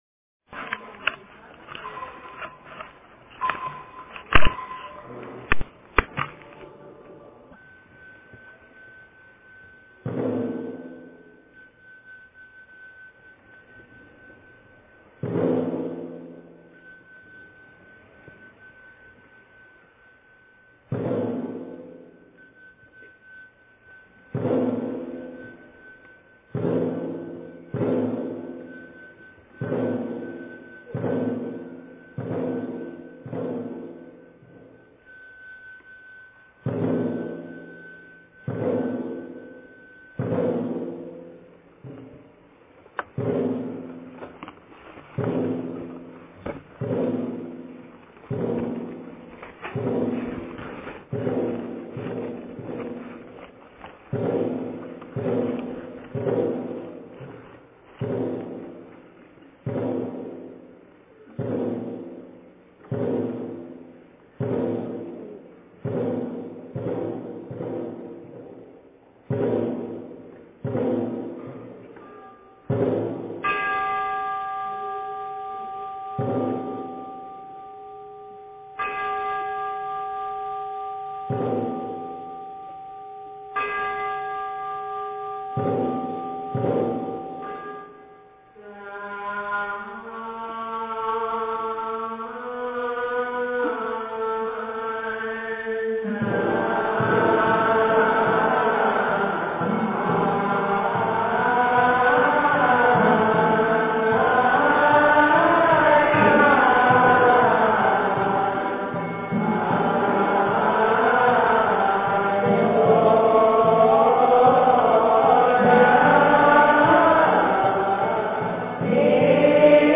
大乘金刚般若宝忏法卷下--金光明寺 经忏 大乘金刚般若宝忏法卷下--金光明寺 点我： 标签: 佛音 经忏 佛教音乐 返回列表 上一篇： 晨钟偈--圆光佛学院众法师 下一篇： 晚课--僧团 相关文章 观世音菩萨普门品--寺院木鱼唱诵版 观世音菩萨普门品--寺院木鱼唱诵版...